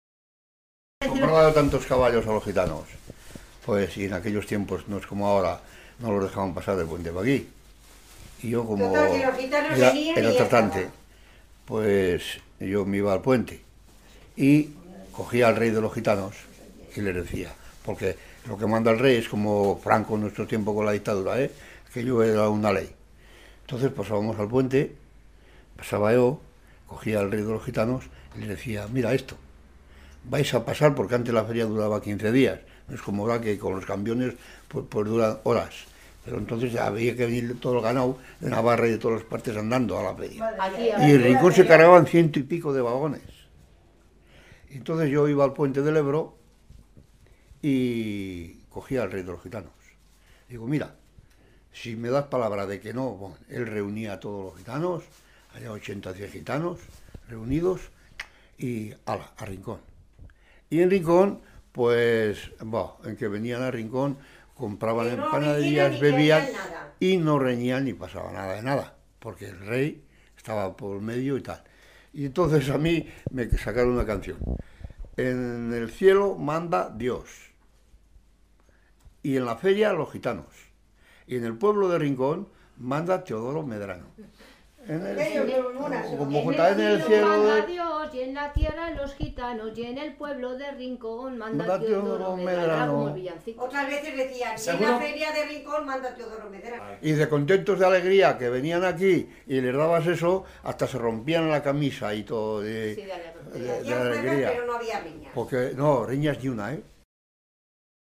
Clasificación: Testimonios etnográficos
Lugar y fecha de grabación: Rincón de Soto, 14 de febrero de 2002